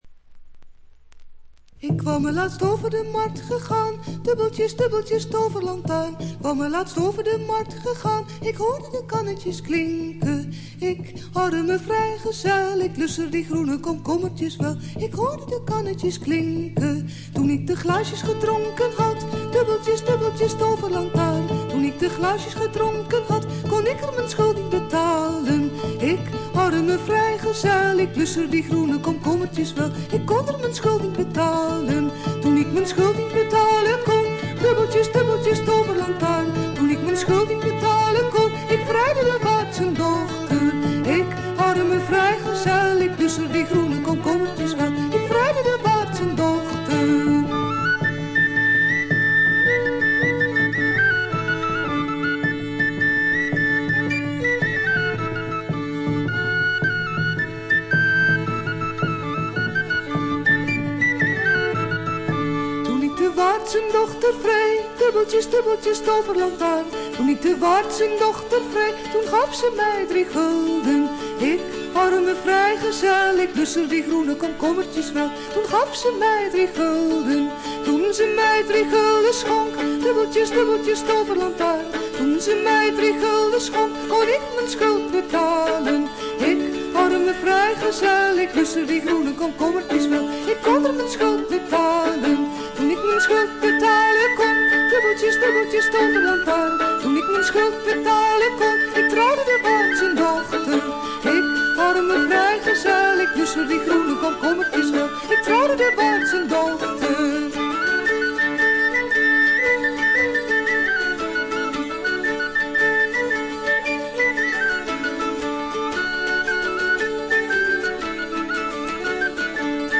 ほんのわずかなノイズ感のみ。
オランダのトラッド・グループ
フィメール入りの4人組で
アコースティック楽器のみのアンサンブルで美しいトラディショナル・フォークを聴かせます。
試聴曲は現品からの取り込み音源です。